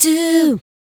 Dooh 120-E.wav